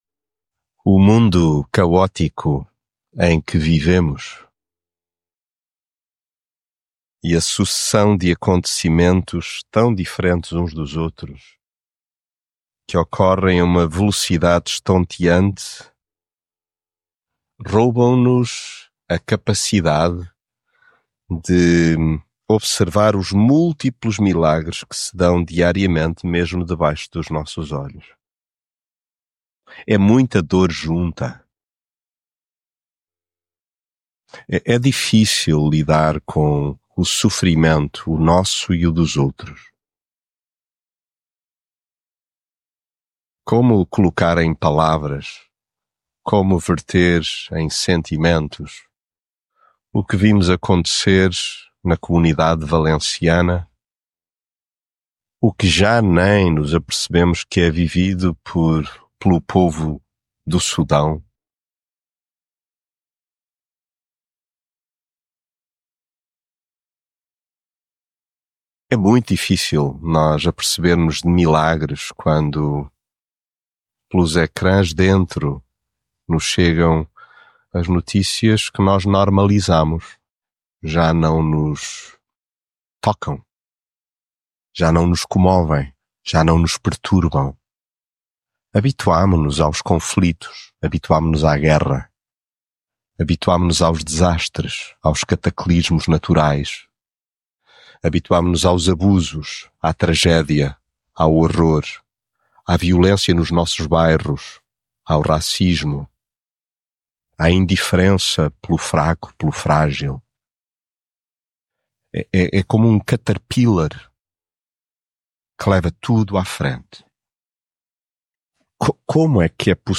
mensagem bíblica O mundo caótico em que vivemos e a sucessão de acontecimentos díspares que ocorrem a uma velocidade estonteante roubam-nos a capacidade de observar...